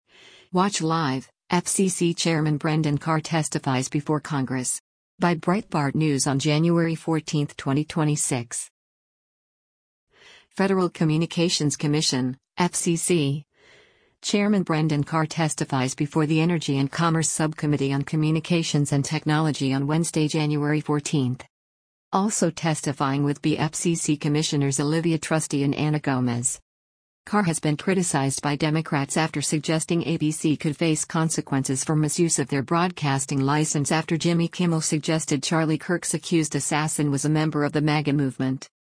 Federal Communications Commission (FCC) Chairman Brendan Carr testifies before the Energy and Commerce Subcommittee on Communications & Technology on Wednesday, January 14.
Also testifying with be FCC Commissioners Olivia Trusty and Anna Gomez.